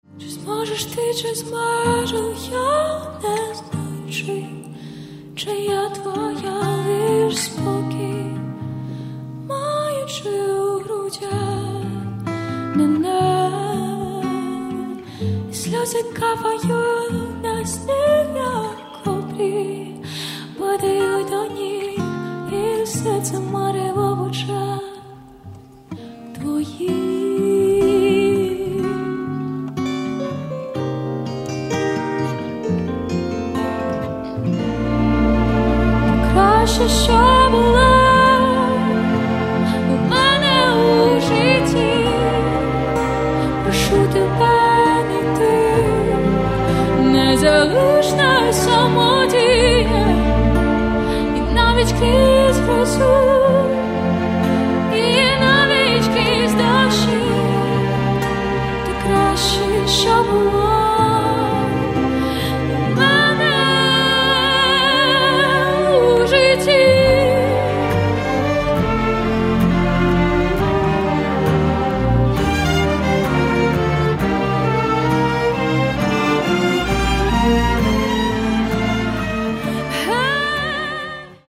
Каталог -> Рок та альтернатива -> Ліричний андеграунд
Простір – ось чим бере за душу цей альбом.